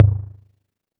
808 L Tom 4.wav